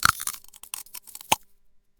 Crunch! …That loud, crisp snap as my teeth sink into a fresh, juicy apple.
The skin breaks, the flesh pops, and the sound echoes in the silence.
Each bite is clean, sharp, and satisfying.”
오히려 과자를 먹을 때 생기는 소리도 있는 것 같고 가장 사과를 베어 물때 어떤 효과음이 잘 어울릴지 고민하고 선택하시면 되겠죠?